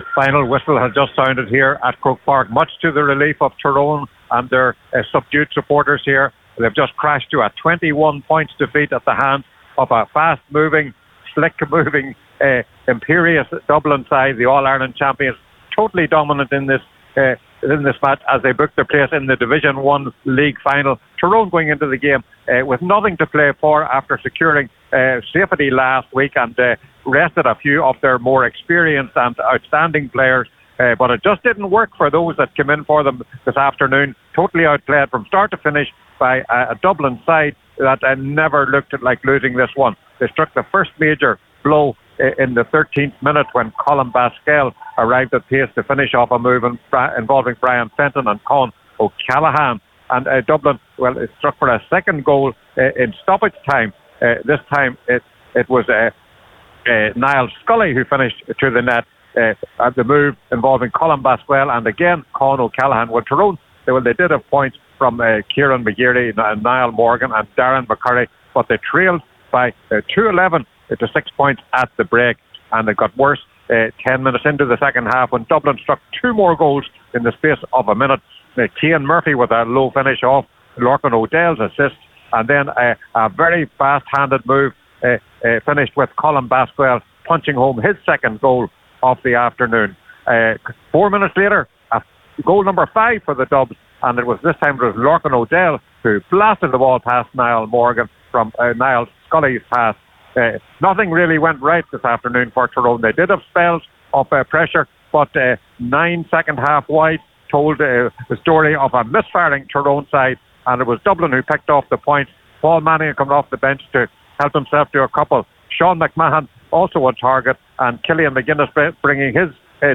reported live for Highland Radio Sunday Sport…